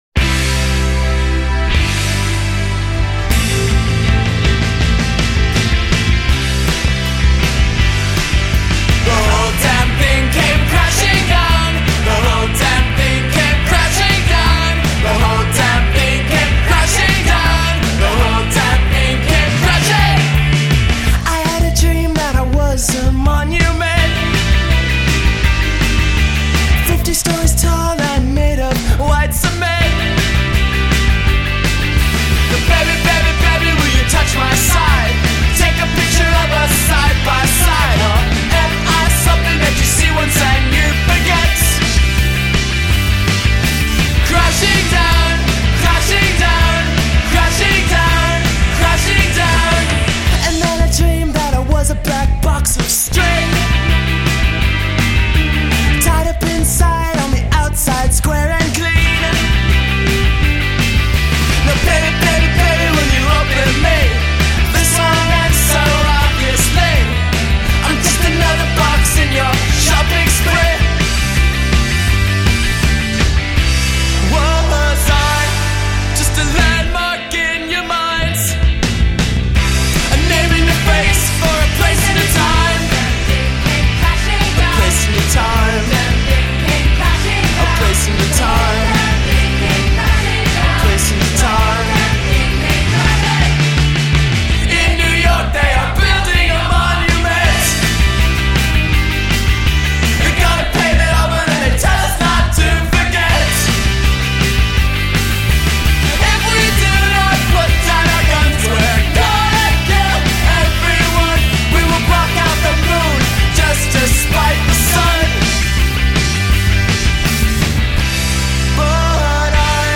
more brit-poppy.